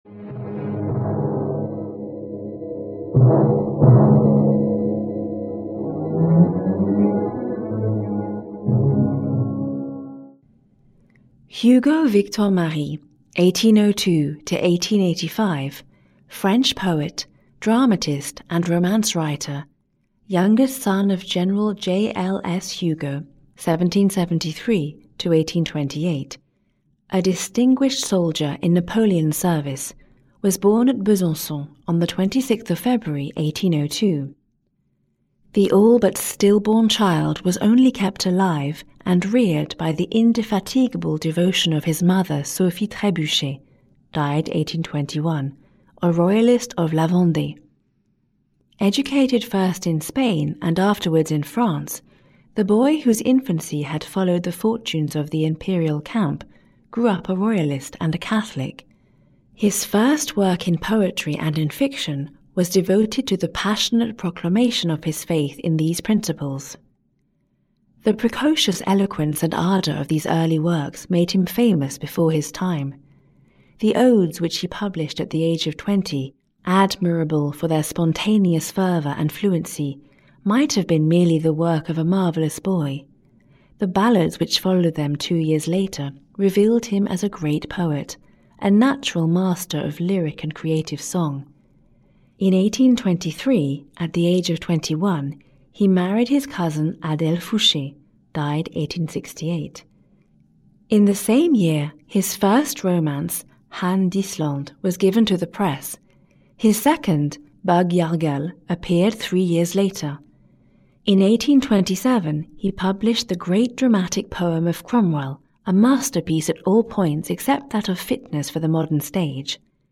Victor Hugo, a Biography (EN) audiokniha
Ukázka z knihy